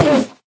sounds / mob / endermen / hit1.ogg
hit1.ogg